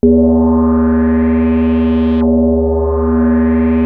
JUP 8 E4 9.wav